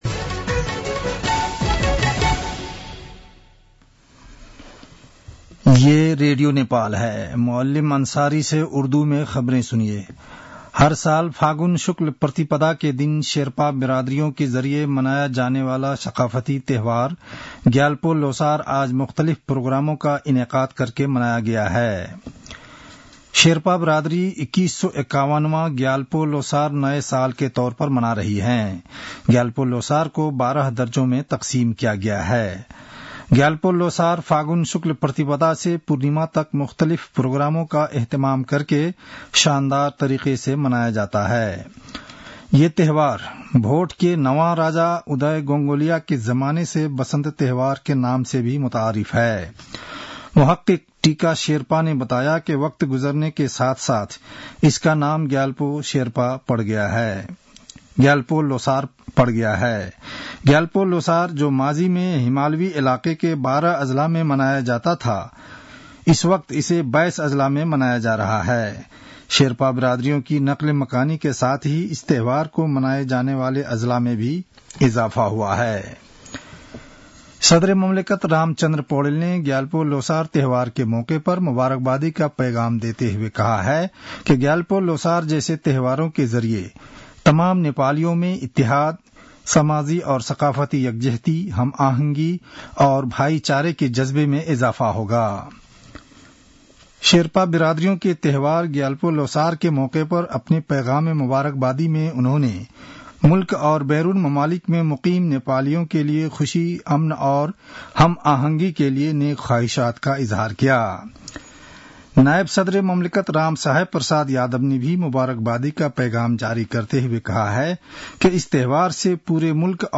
उर्दु भाषामा समाचार : १७ फागुन , २०८१
Urdu-news-11-16.mp3